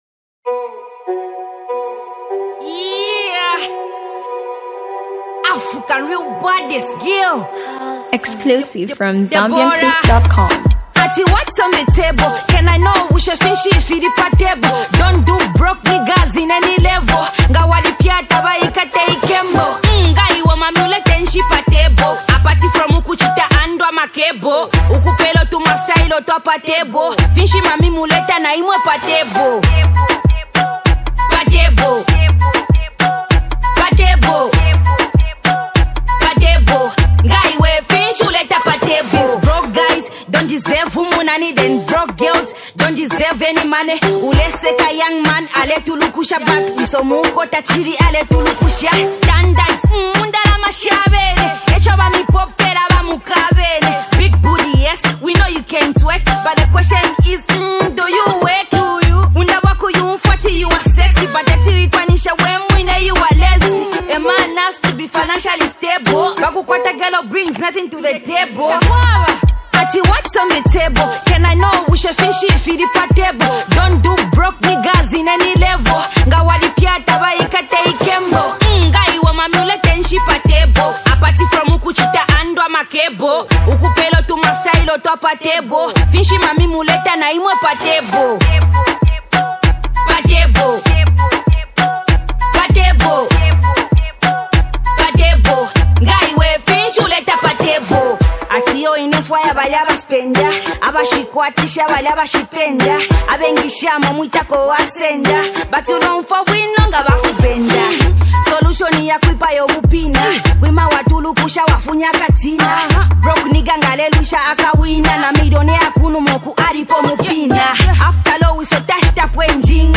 country groove and clubbing jam